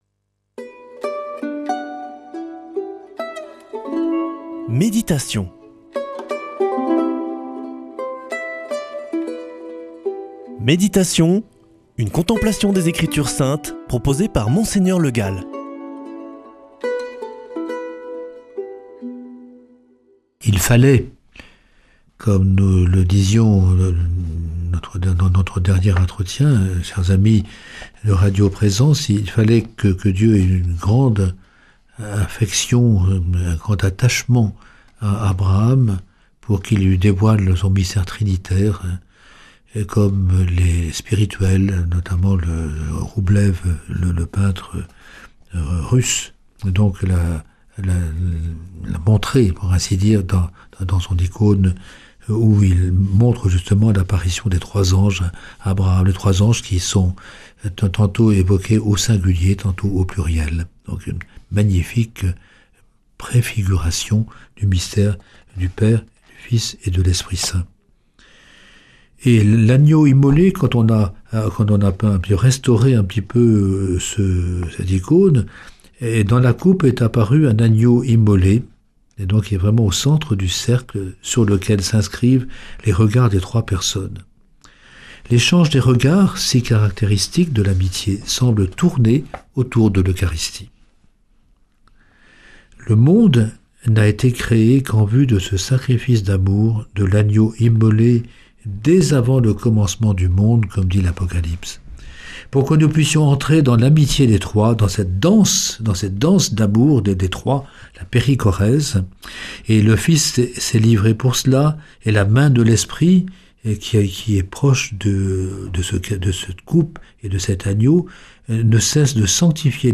Méditation avec Mgr Le Gall
Présentateur